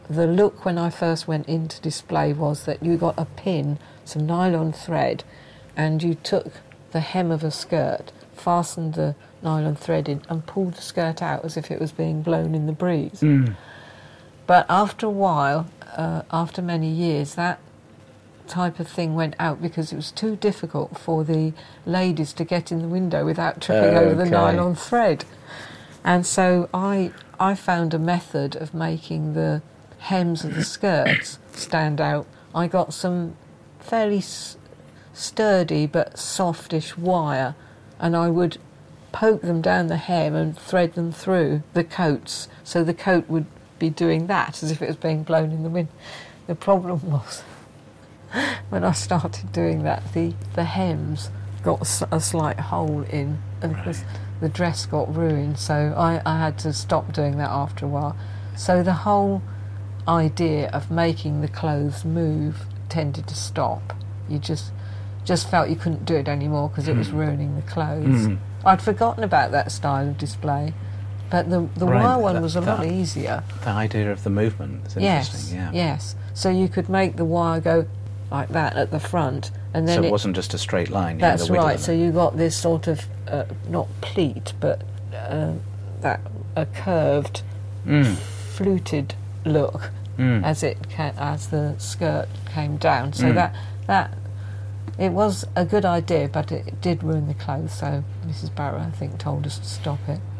Packs' People Oral History project